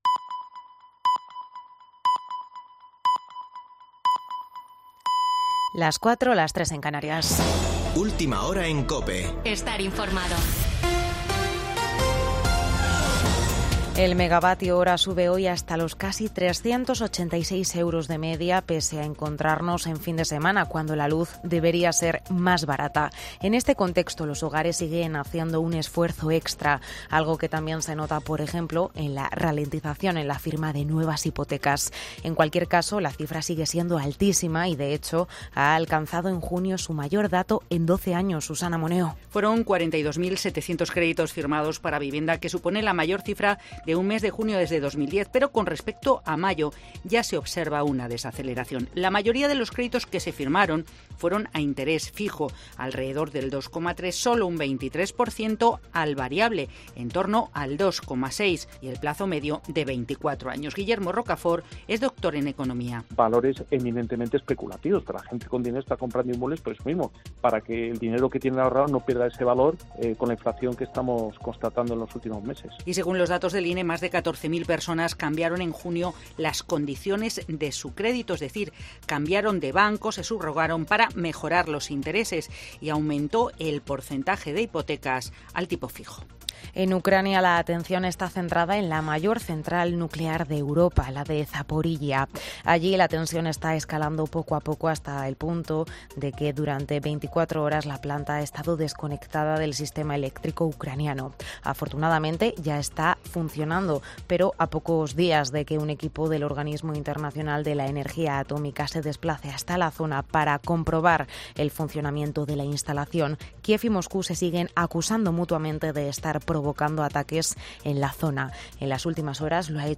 Boletín de noticias de COPE del 27 de agosto de 2022 a las 04.00 horas